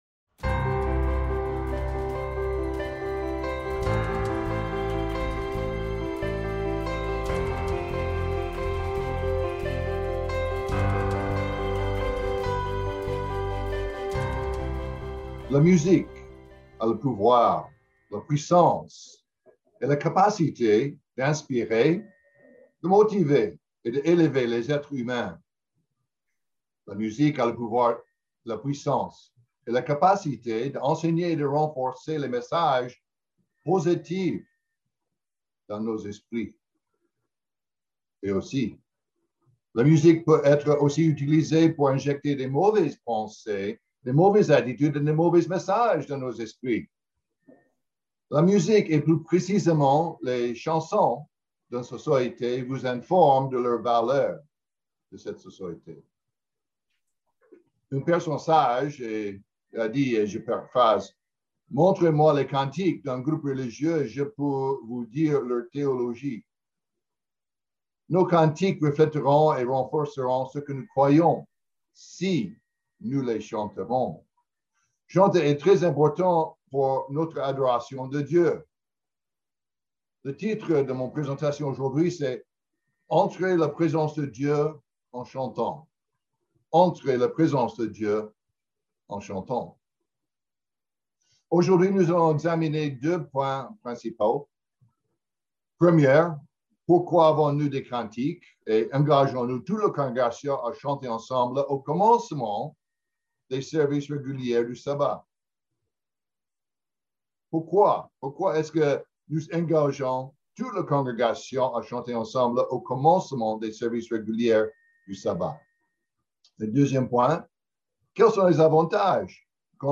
Sermons
Given in Bordeaux